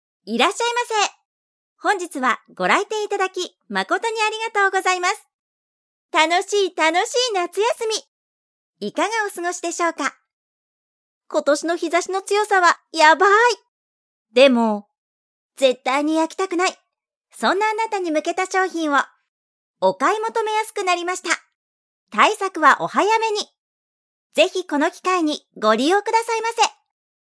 早速、今月追加されたフレーズから、サンプルデータを作ってみました！